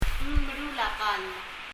meruul　　　　[mɛru:l]　　準備する、修理する、作る　prepare,fix,repair